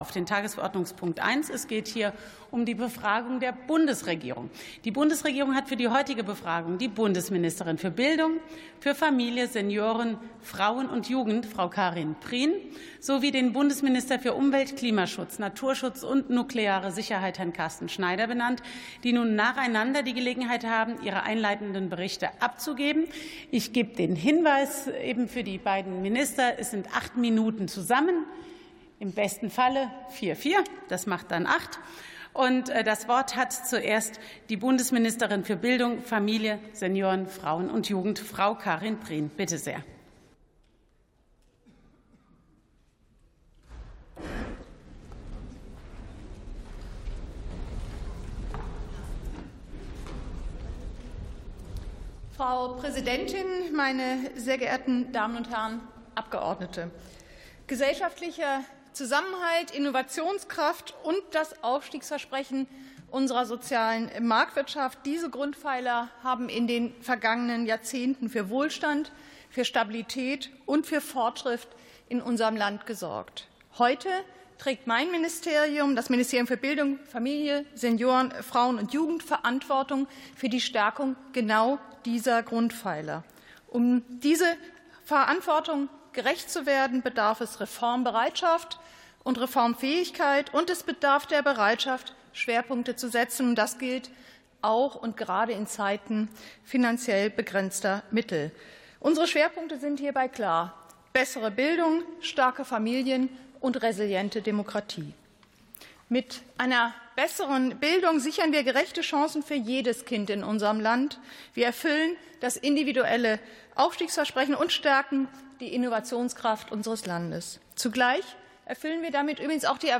Befragung der Bundesregierung (BMBFSFJ und BMUKN)